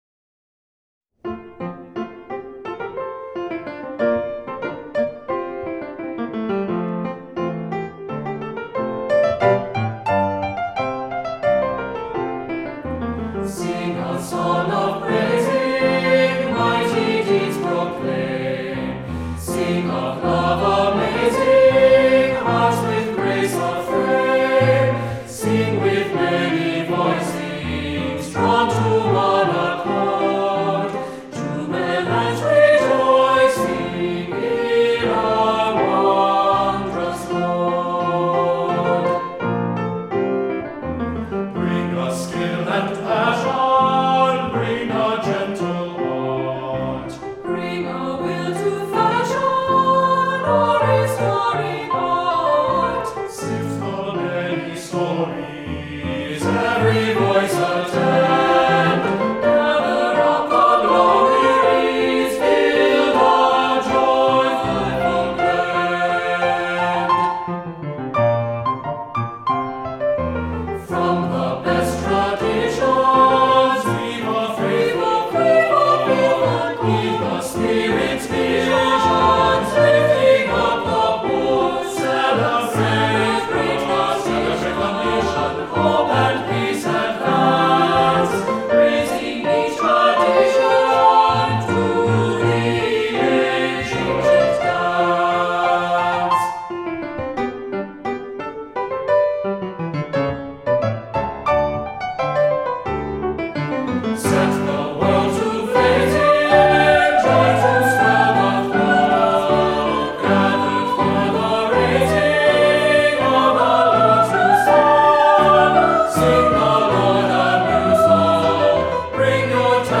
Voicing: Descant,SAB